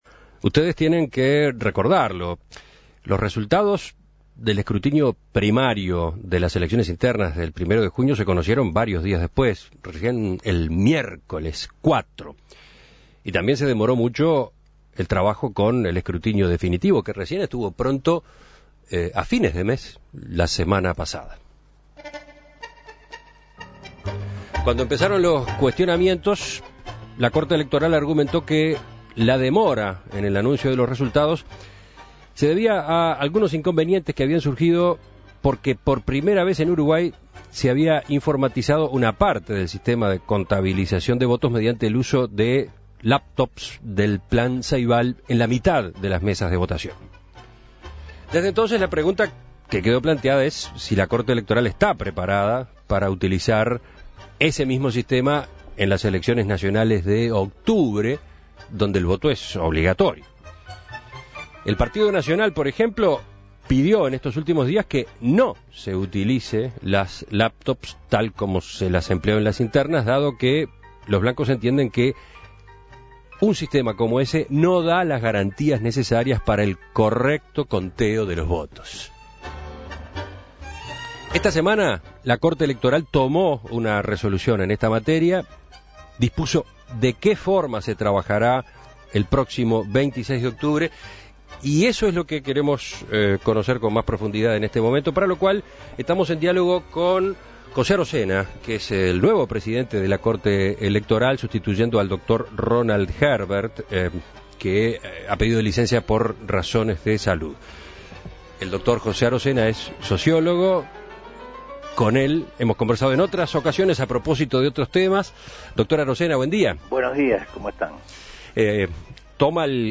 Para conocer cómo se prepara la Corte Electoral de cara a octubre, En Perspectiva entrevistó al presidente de la Corte Electoral, José Arocena.